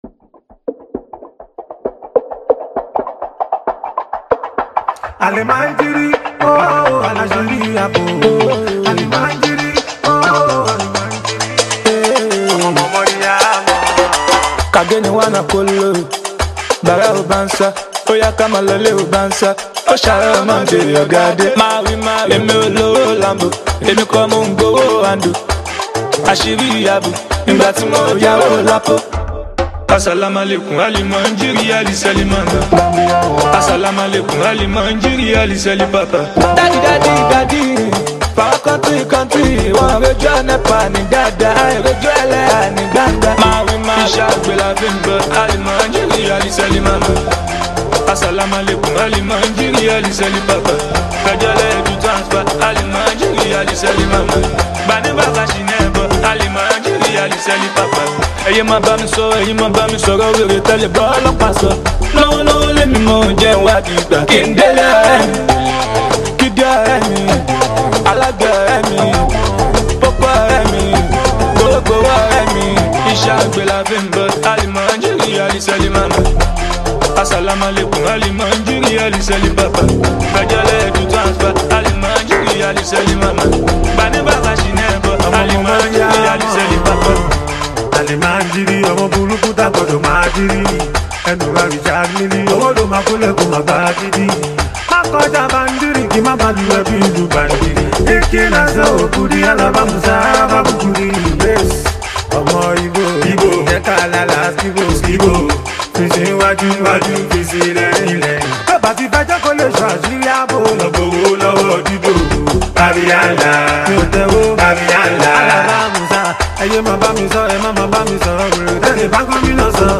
Nigeria talented Afrobeats singer and songwriter